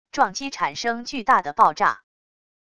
撞击产生巨大的爆炸wav音频